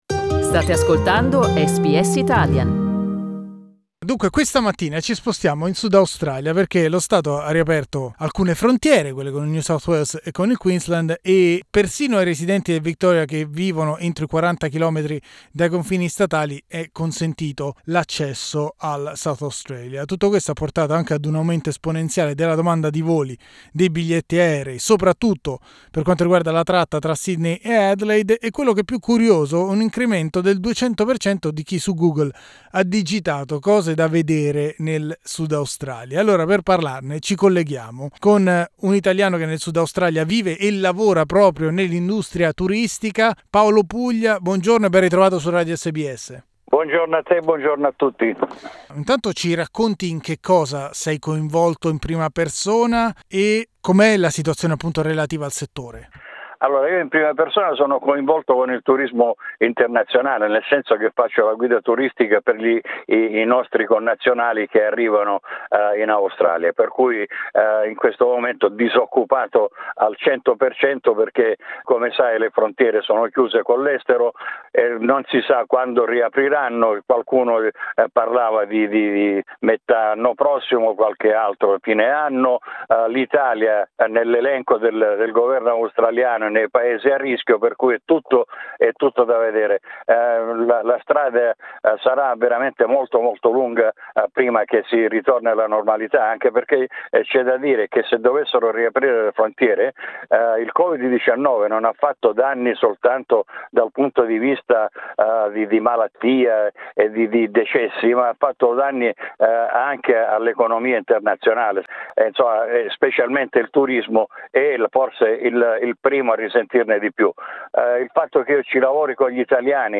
Lo abbiamo chiesto ad una guida turistica.